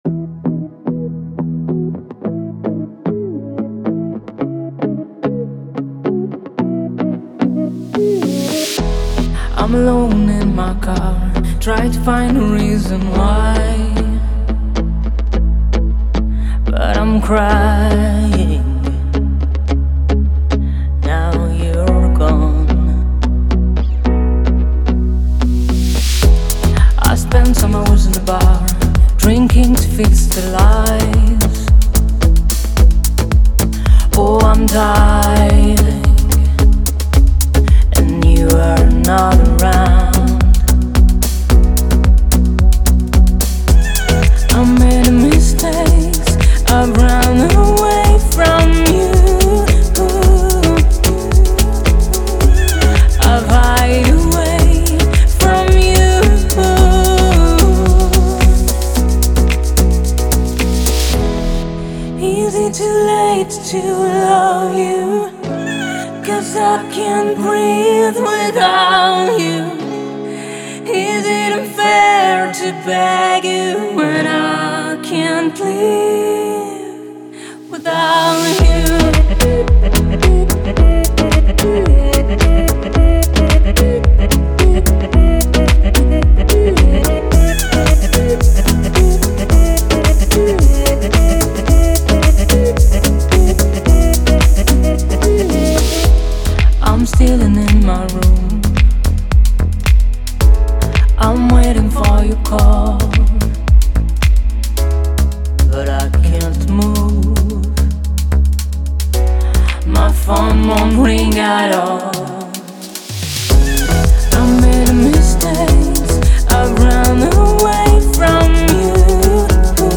это эмоциональная электронная композиция
которая сочетает в себе элементы транс и хаус-музыки.